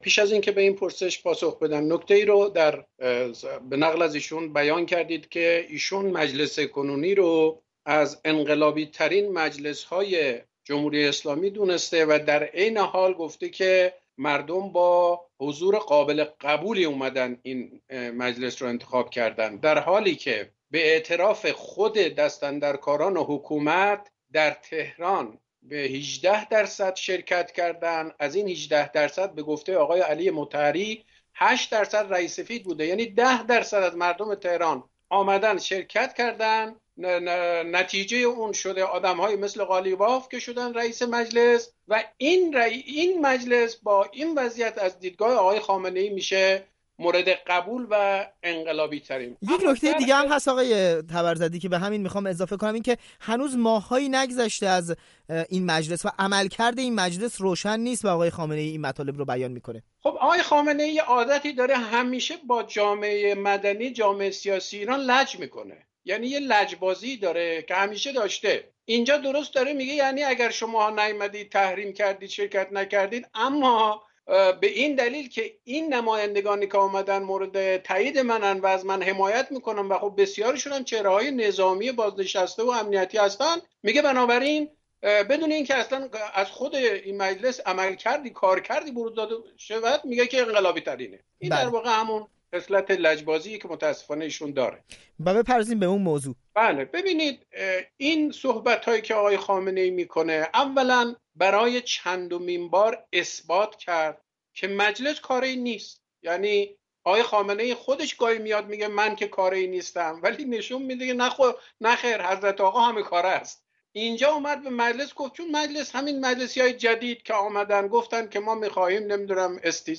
رهبر جمهوری اسلامی در دیدار با نمایندگان مجلس با تأکید بر فعالیت دولت‌ها تا پایان دوره قانونی، تلویحاً با استیضاح روحانی مخالفت کرد. گفت‌وگو